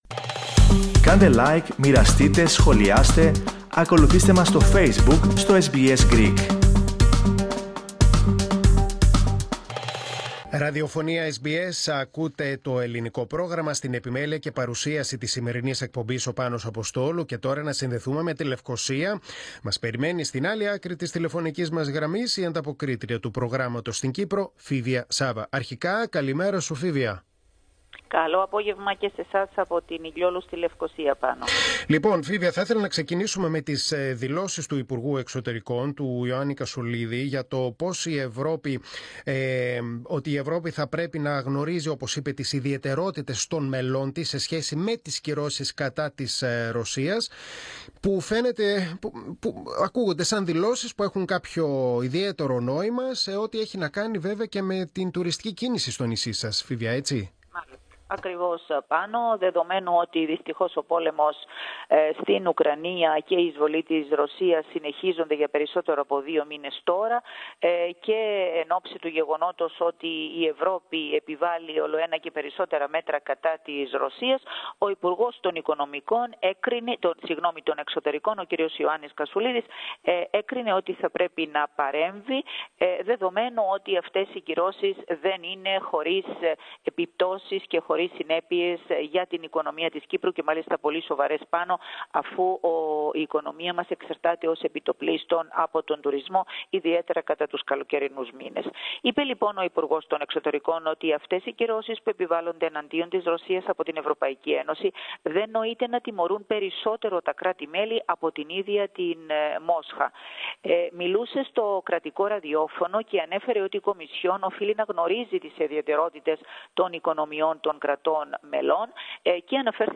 Ακούστε ολόκληρη τη συνέντευξη από την Κύπρο, πατώντας το σύμβολο στο μέσο της κεντρικής φωτογραφίας.